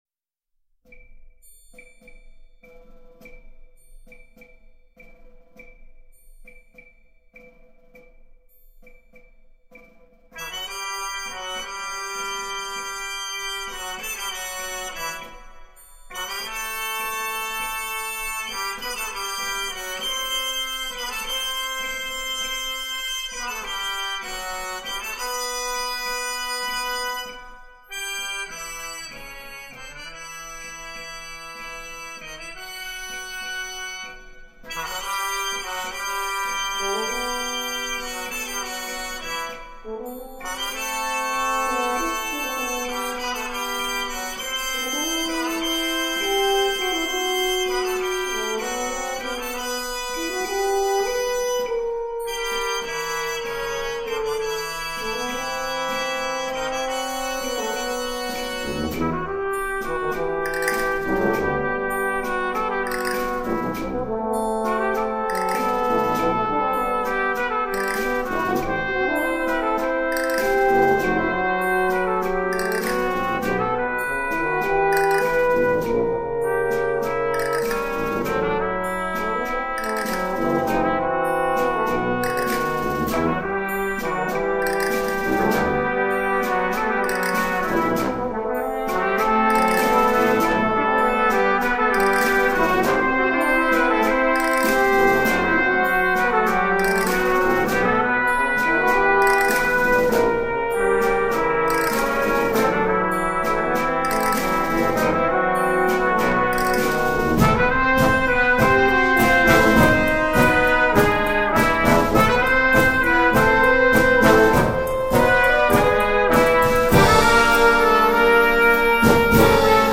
Instrument: brass tentet (score and parts)